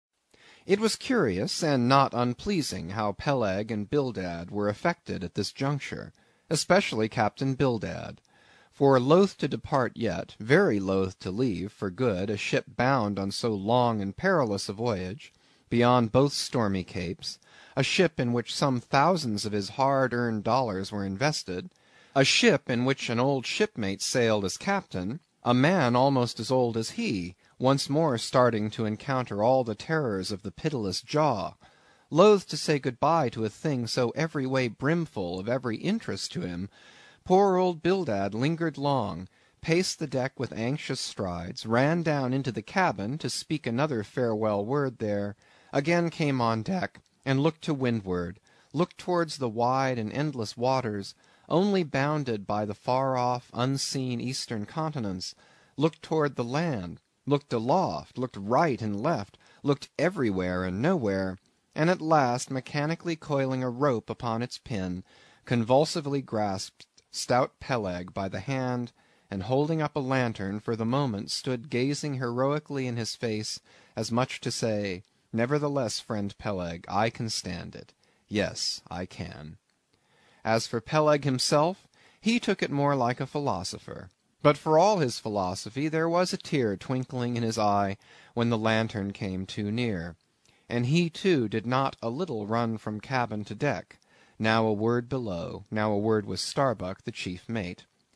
英语听书《白鲸记》第329期 听力文件下载—在线英语听力室